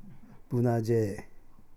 スピーカぶなじぇー〈※多良間島の始祖の兄妹の名〉（多良間方言）
je/zye ジェー・イー/ゼット・ワイ・イー